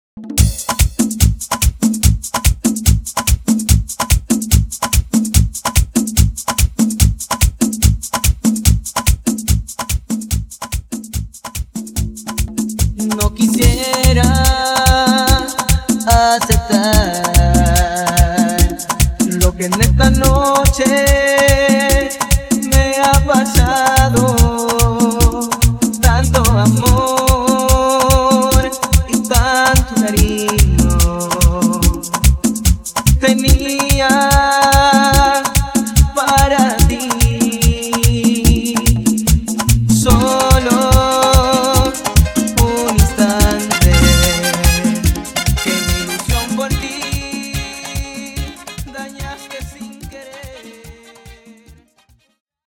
Etiqueta: Merengue